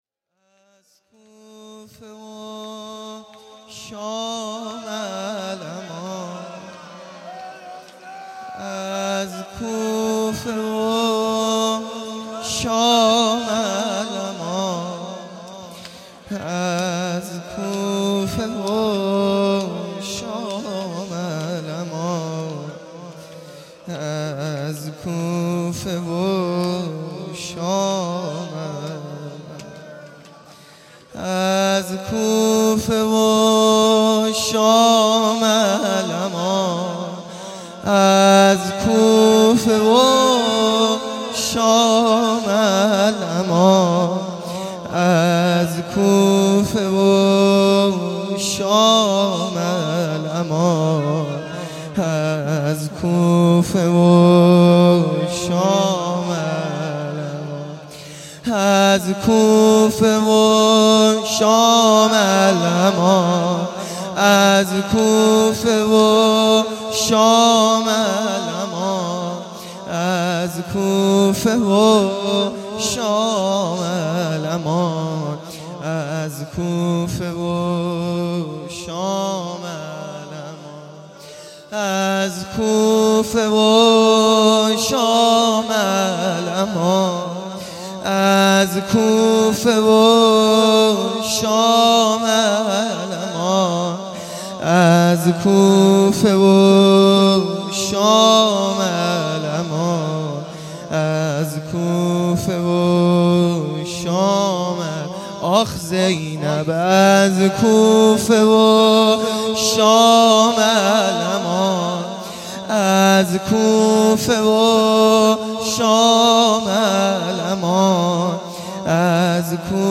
دهه اول صفر | شب اول